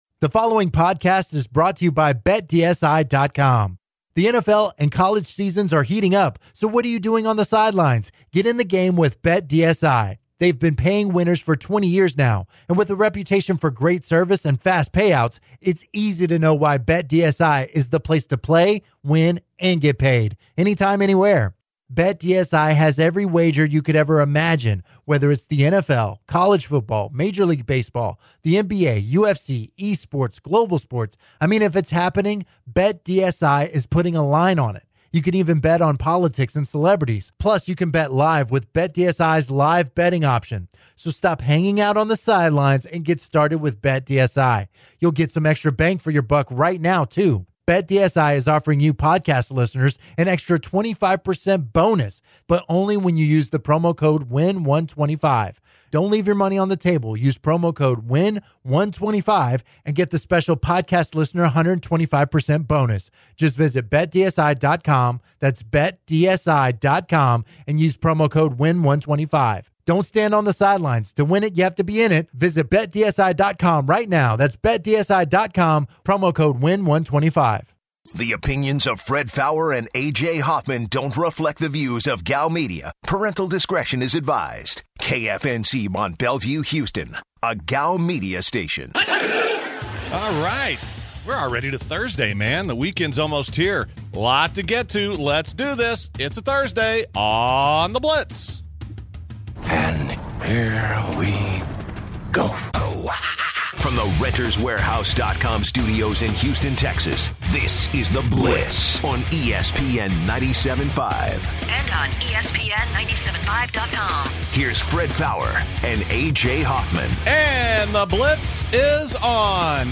The guys take a bunch of phone calls during a discussion on sports etiquette. The guys finish the first hour with a little football talk.